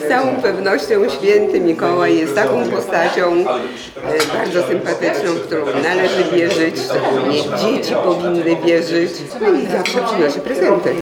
To już piękna świąteczna tradycja – wigilia w Radiu 5 Ełk.